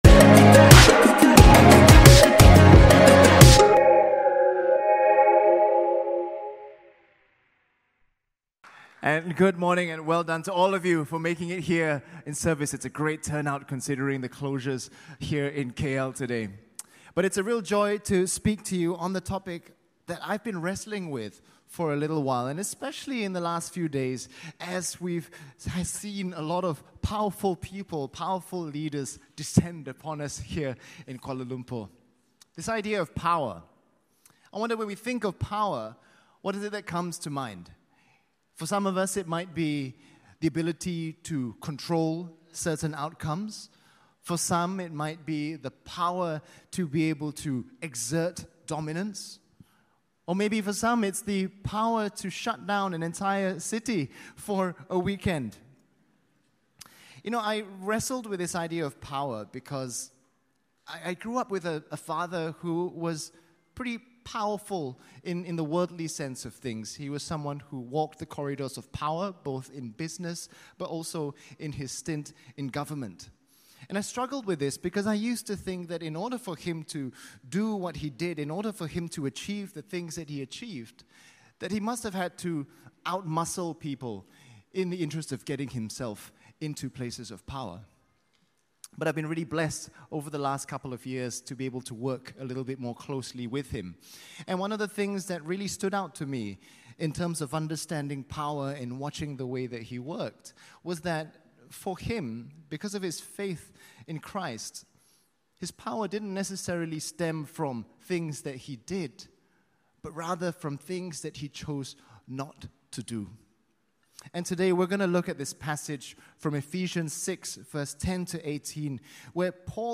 SERMON+ENG_26+OCT.MP3